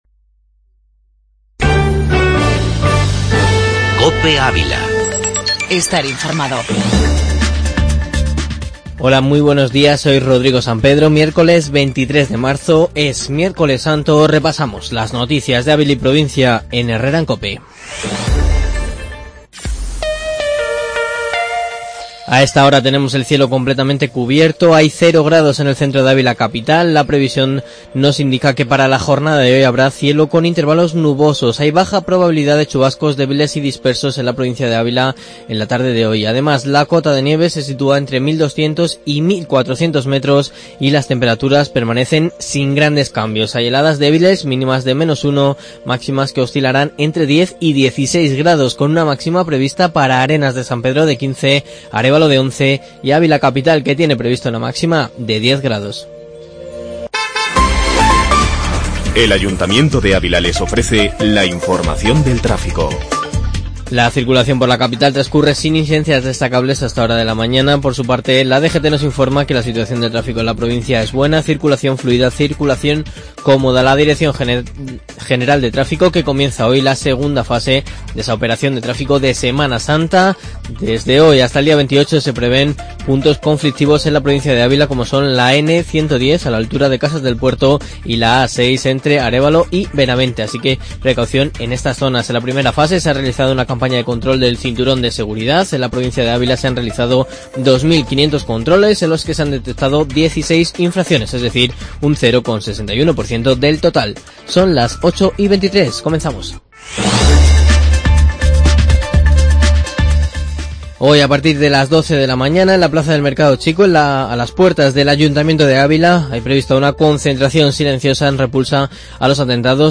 Informativo matinal en 'Herrera en Cope'.